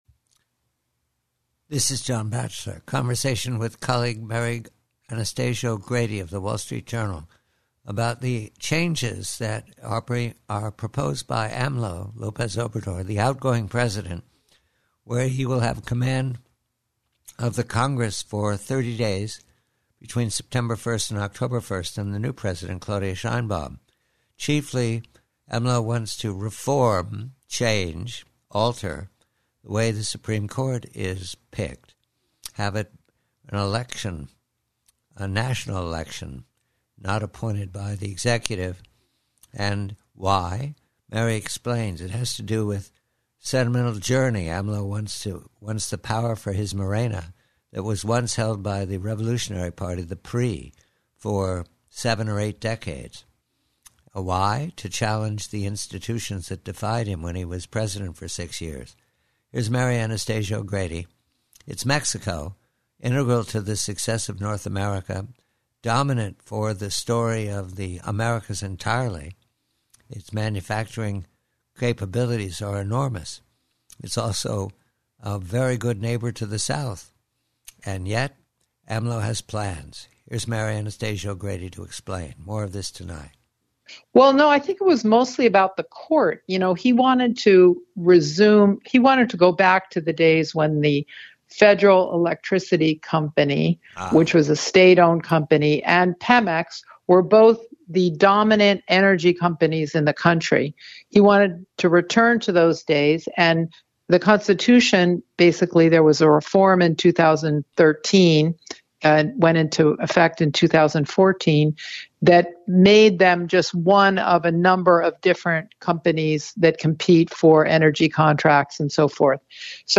PREVIEW: MEXICO Conversation with Mary Anastasia O'Grady of WSJ regarding the ambition of retiring President López Obrador to render the courts obedient to his dream of an all-powerful Morena Party that can dominate the energy sector and all else.